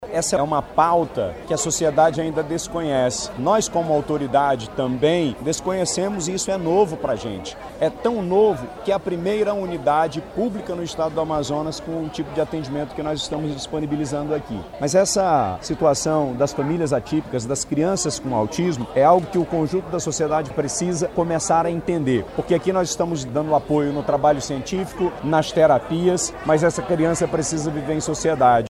A proposta é consolidar uma política pública continuada voltada à inclusão e à saúde da criança com deficiência, como explica o Governador do Amazonas, Wilson Lima.